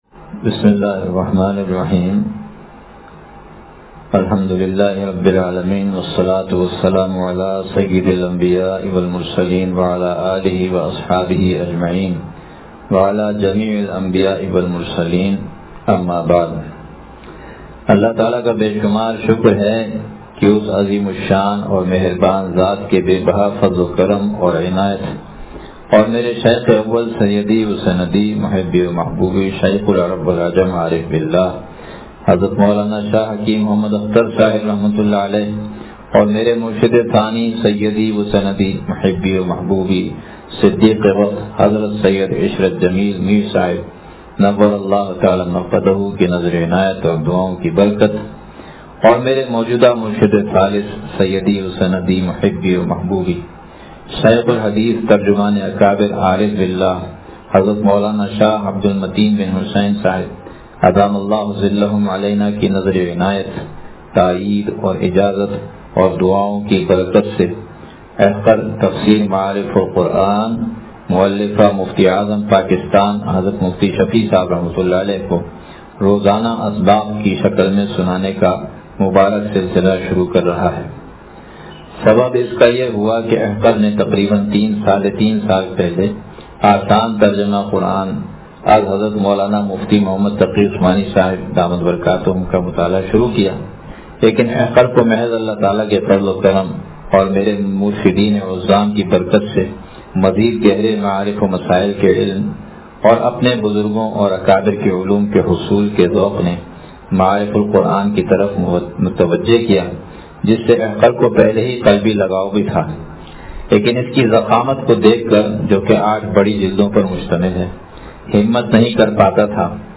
بیان بعد نمازِ عشاء مدنی مسجد پھلیلی حیدرآباد